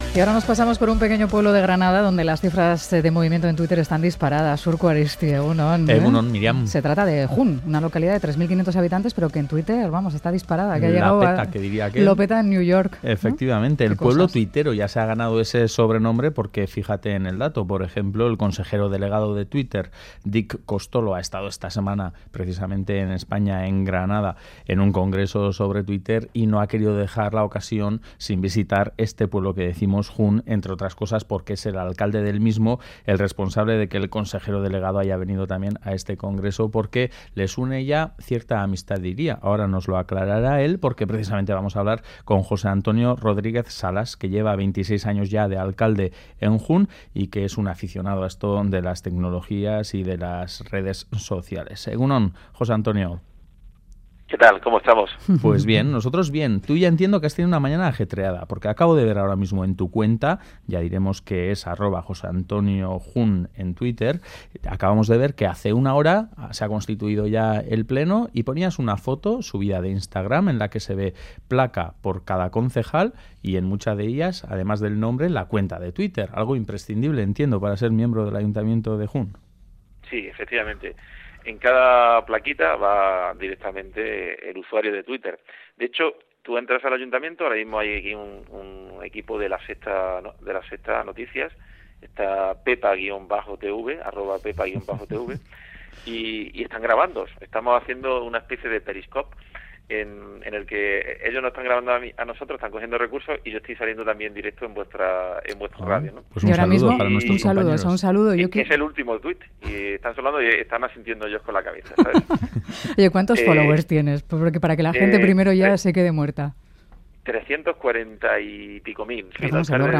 Hablamos con su alcalde, José Antonio Rodríguez el alcalde con más followers de todo Europa.